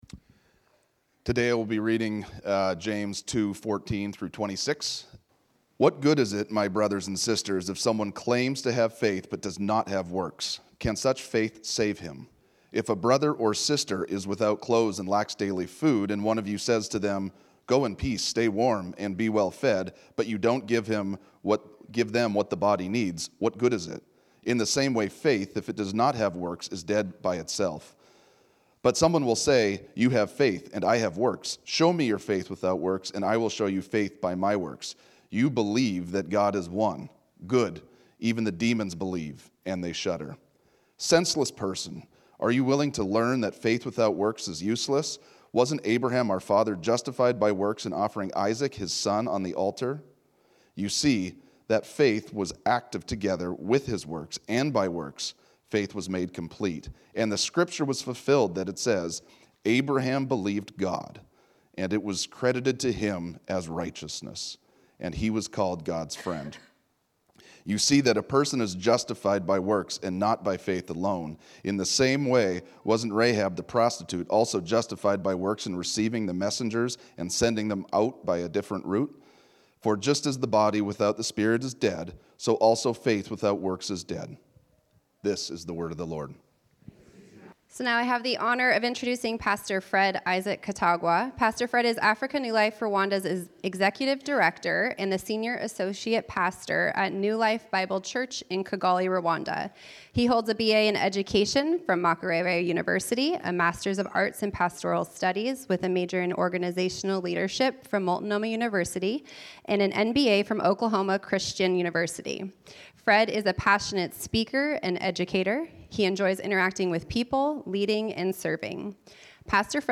This sermon was originally preached on Sunday, October 26, 2025.